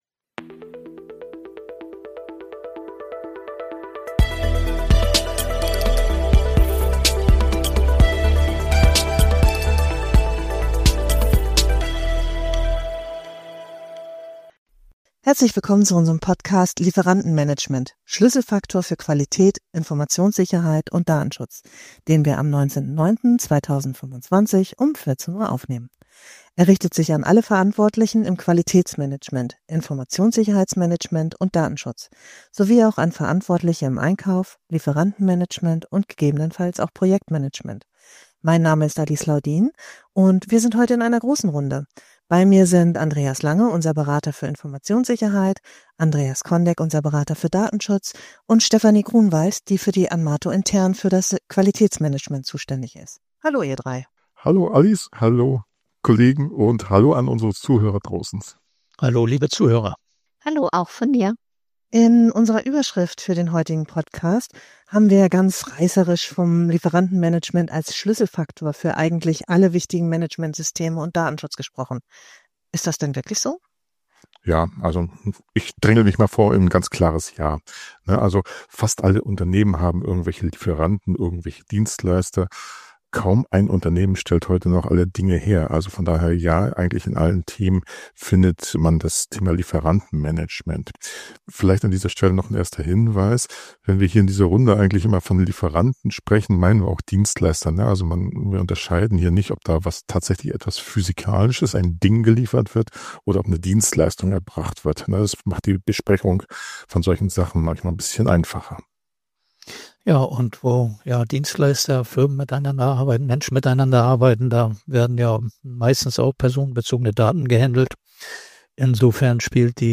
In dieser Folge diskutieren unsere Experten, warum Lieferantenmanagement nicht nur ein Randthema, sondern ein Schlüsselfaktor für Unternehmen ist.